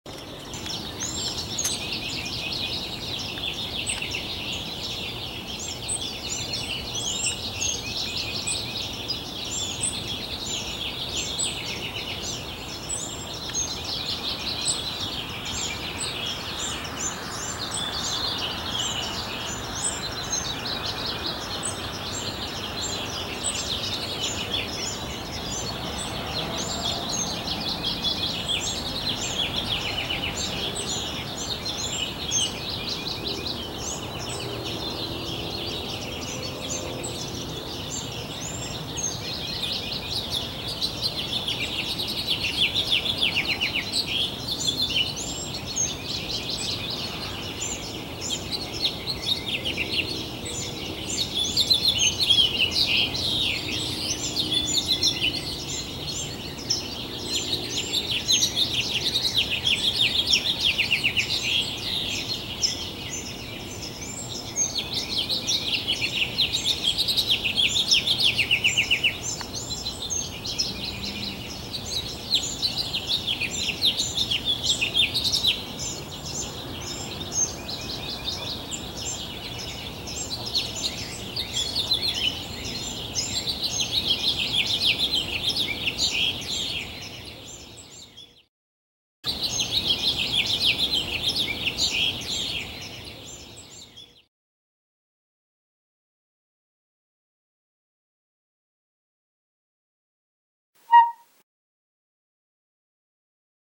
This is a video with glorious birdsong in the morning. The location was the San Fernando Valley, California.
This is birdsong in the morning.
Birdsong in the Morning
By the Songbirds
Location: San Fernando Valley, CA